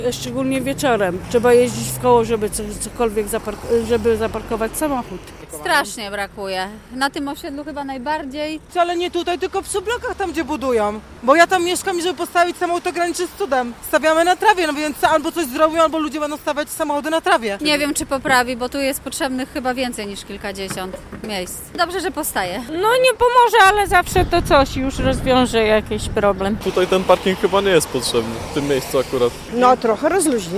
Mieszkańcy cieszą się, że powstaje nowy parking, ale mają wątpliwości czy wystarczy miejsca dla wszystkich: